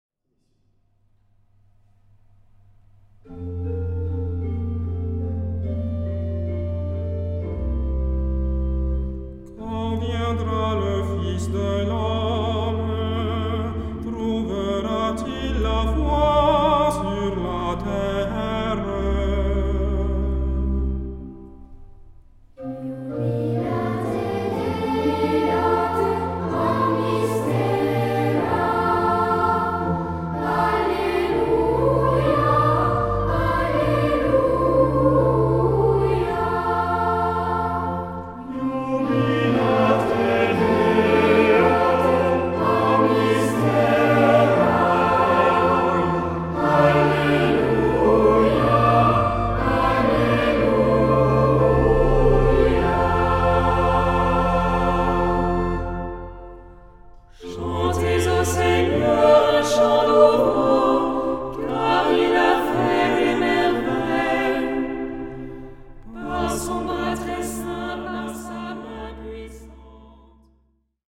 Genre-Stil-Form: Tropar ; Psalmodie
Charakter des Stückes: andächtig
Instrumente: Orgel (1) ; Melodieinstrument (ad lib)
Tonart(en): F-Dur